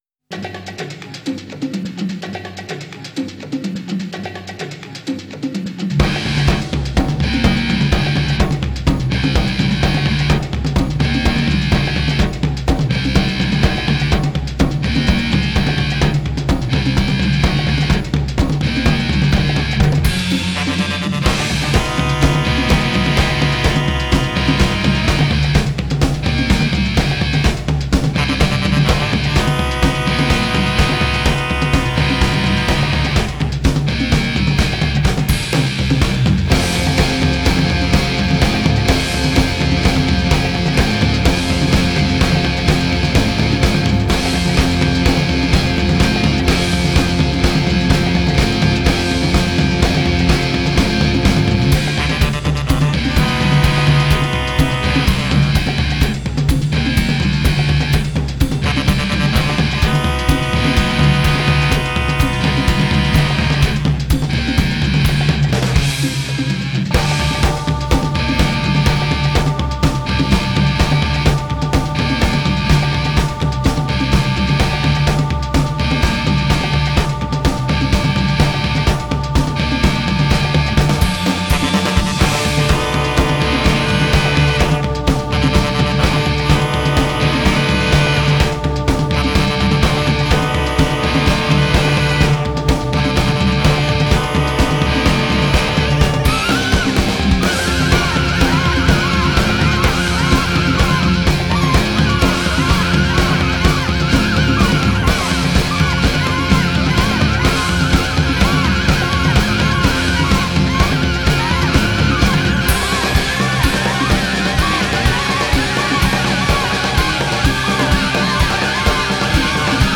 genre: avant-heavy-rock